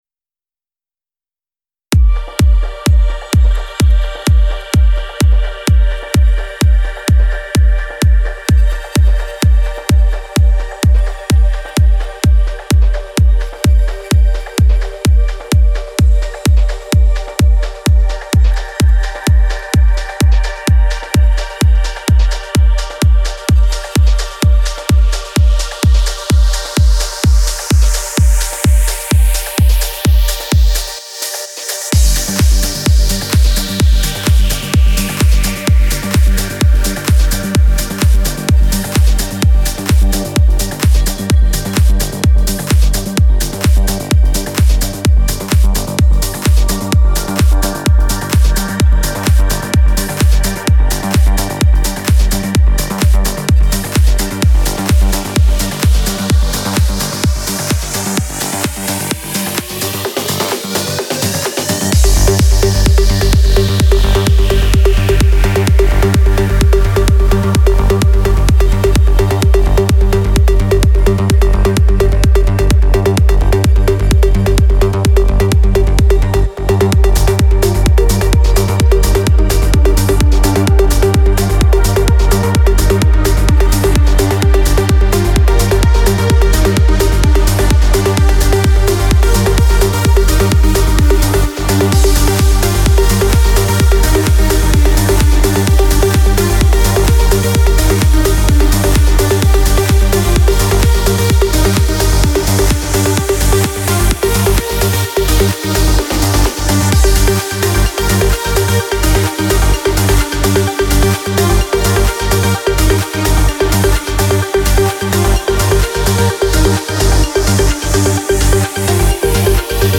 Стиль: Trance / Progressive Trance